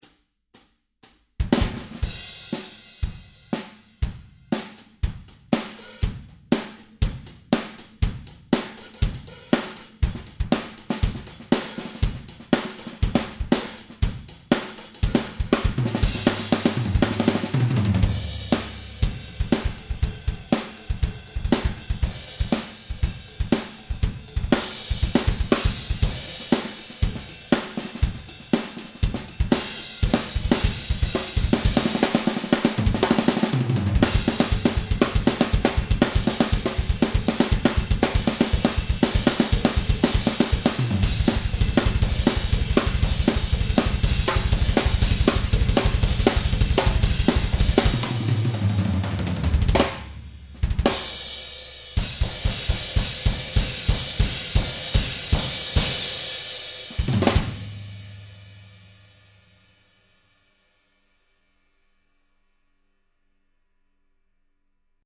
Hierbei handelt es sich um die mitgelieferten Demo Grooves:
Ein straighter Rockgroove mit Improvisationen, 120BPM
ezdrummer-demo-straight-120